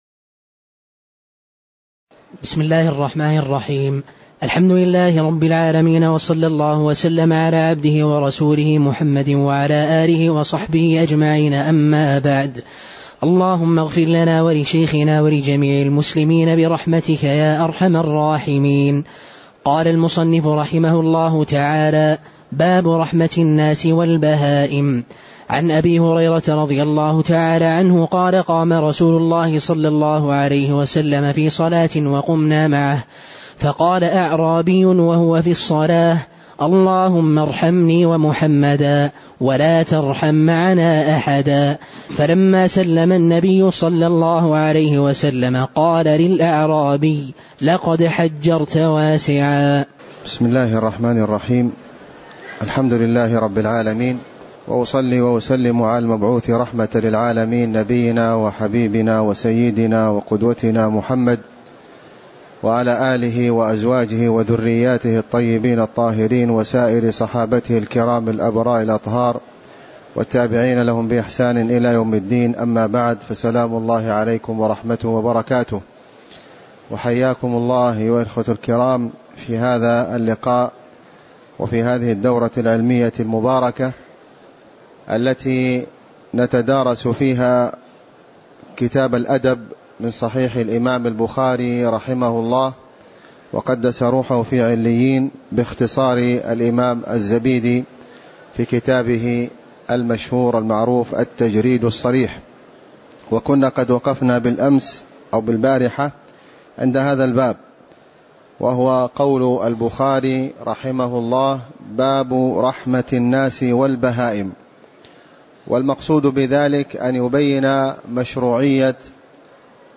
تاريخ النشر ٢٨ ربيع الثاني ١٤٣٩ هـ المكان: المسجد النبوي الشيخ: فضيلة الشيخ د. خالد بن علي الغامدي فضيلة الشيخ د. خالد بن علي الغامدي باب رحمة الناس والبهائم (003)